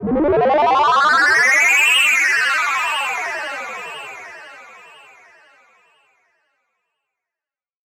Звуковые эффекты магии, трансформации и перевоплощения идеально подойдут для монтажа видео, создания игр, подкастов и других творческих проектов.
Звук главный герой превратился в животное или в нечто иное